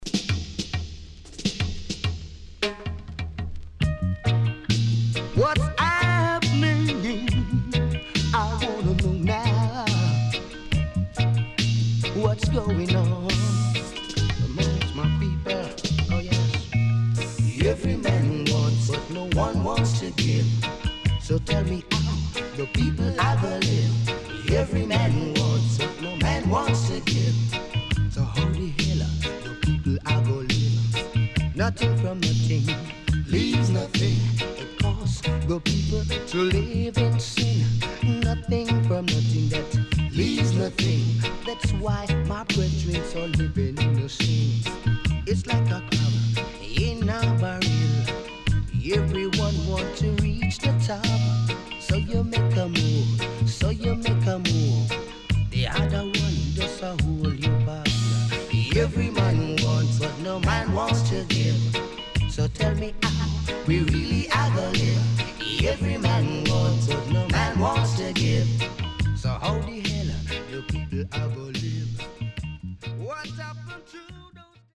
HOME > Back Order [DANCEHALL LP]
SIDE A:少しチリノイズ入りますが良好です。